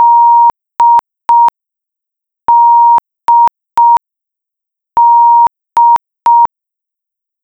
• (1) das FBO1100 anrufen --> es darf sich nicht mit dem bekannten Kuckuck melden, es muss
dieser Ton zu hören sein --> wenn nicht bitte auch das an mich berichten
QTonSeq_Fehler_a.wav